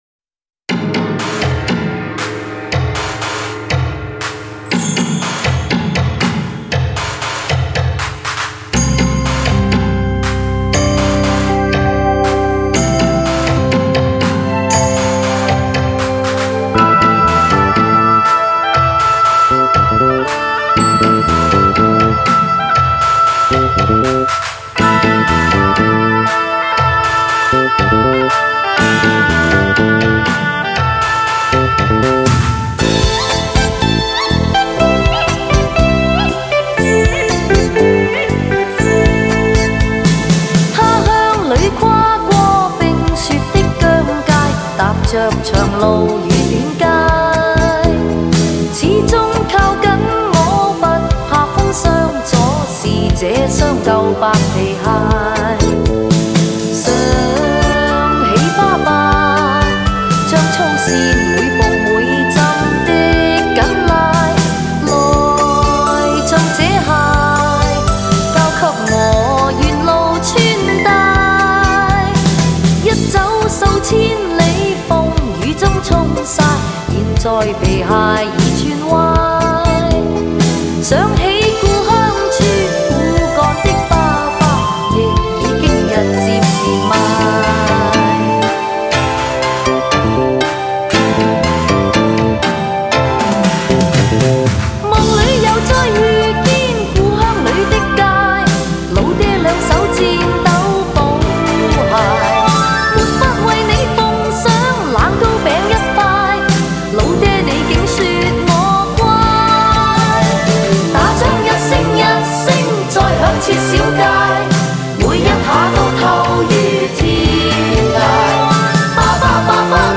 DSD重新数码编制
极品音色 至臻完美